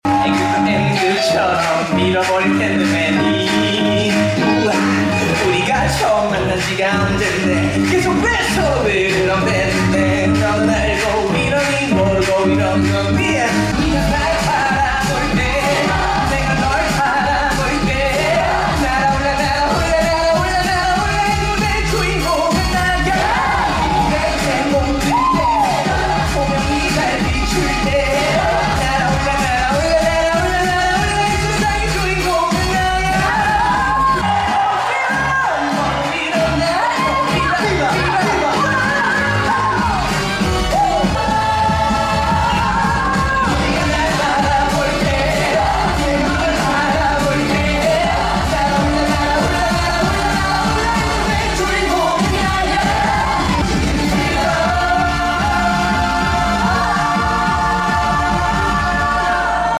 in the encore performances
He is such an incredible performer with extremely versatile vocals.
fancam